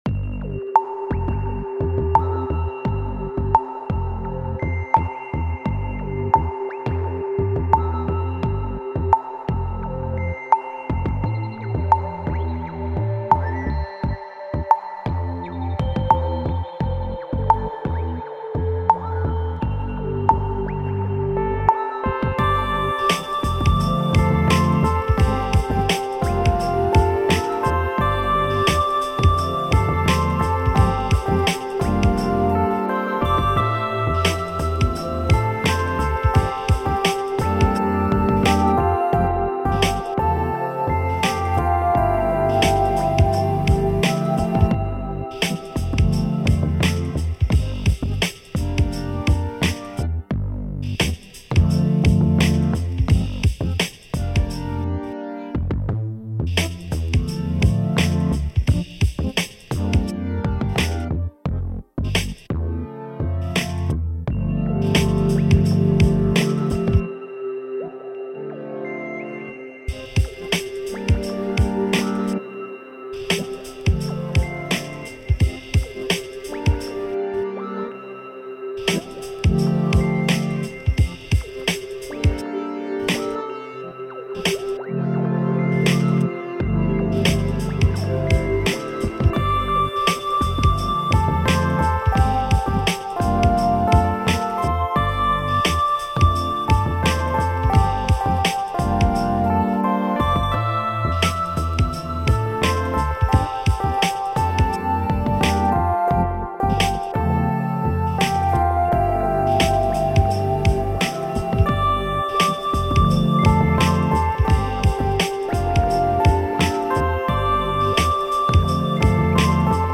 keyboards